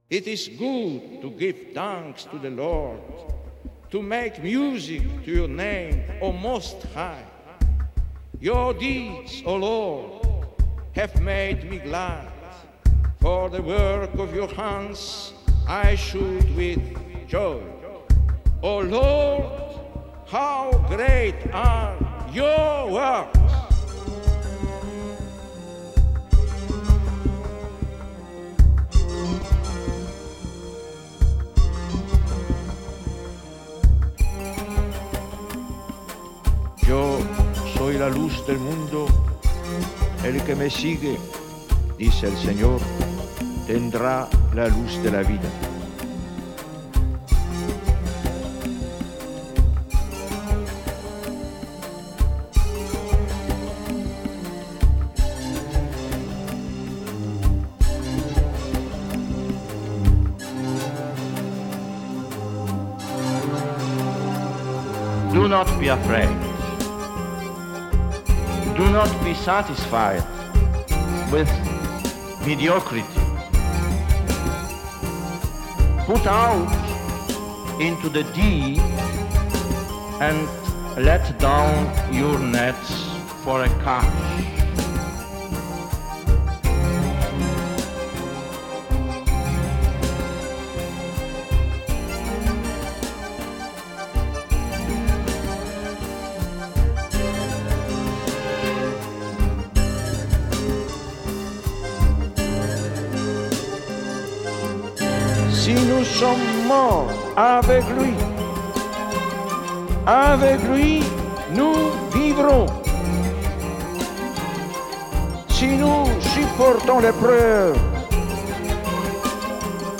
Hearing his voice is calming and refreshing.